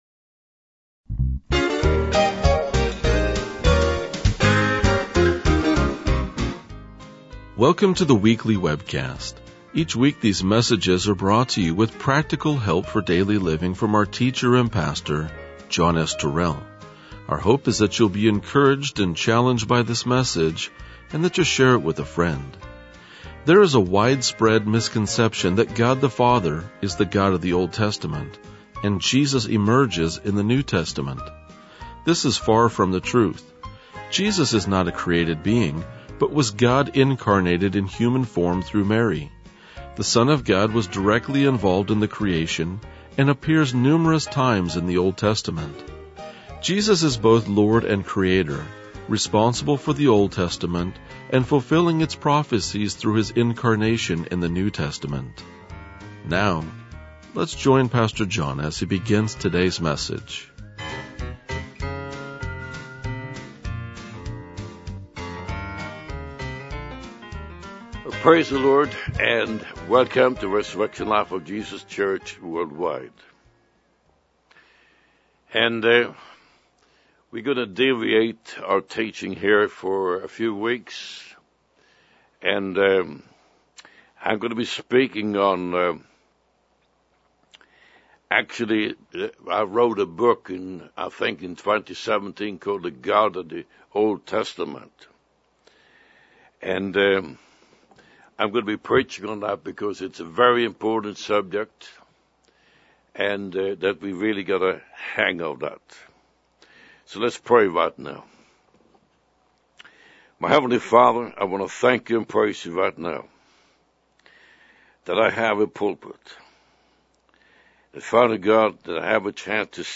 RLJ-2036-Sermon.mp3